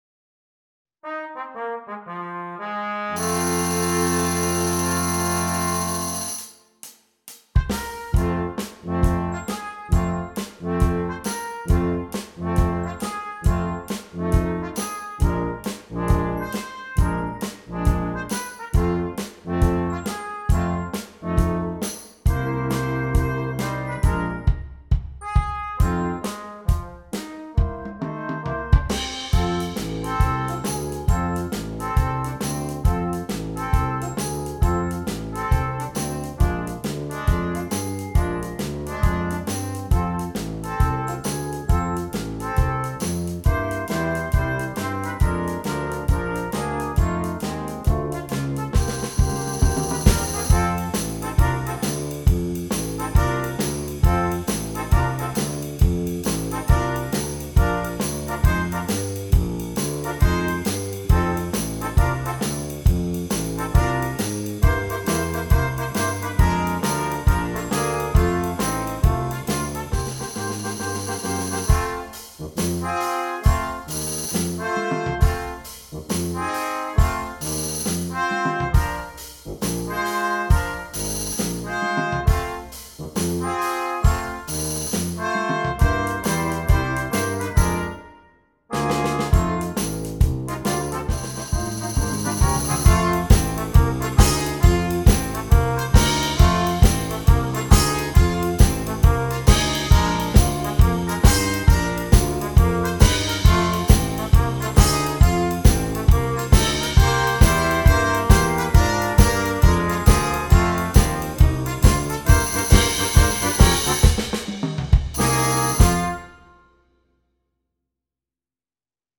Noten für flexibles Ensemble, 4-stimmig + Percussion.